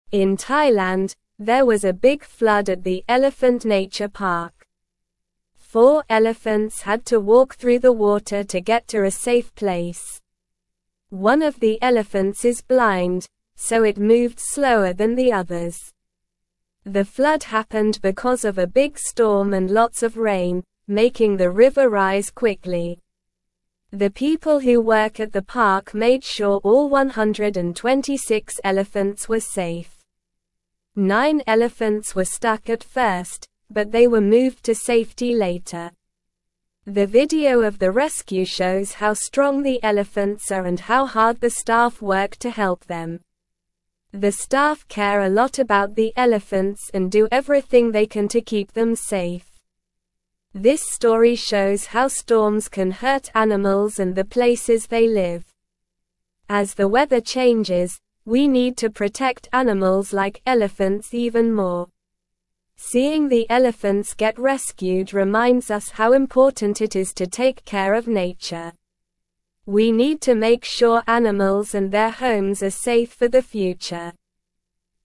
Slow
English-Newsroom-Lower-Intermediate-SLOW-Reading-Elephants-Brave-Flood-in-Thailand-People-Keep-Safe.mp3